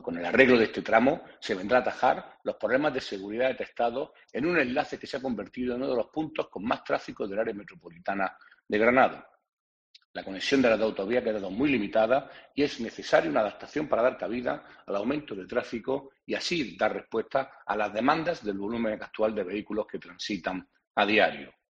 Pablo García, parlamentario del PP